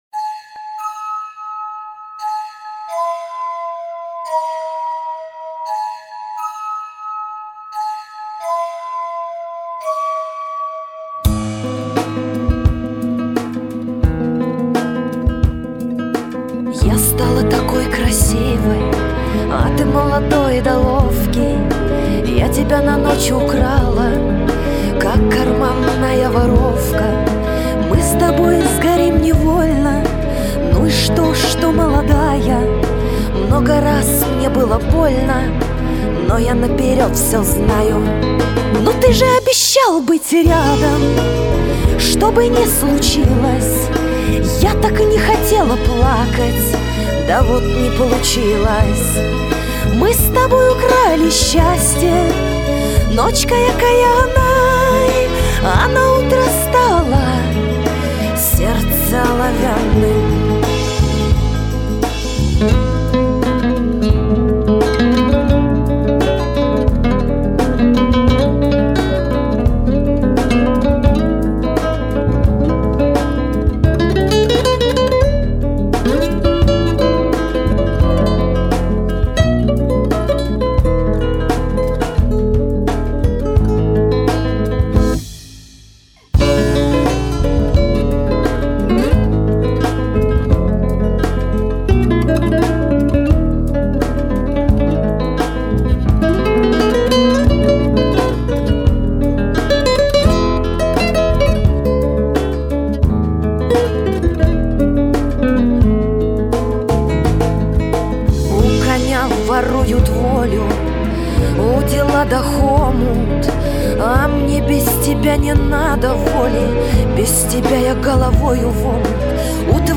Genre: Шансон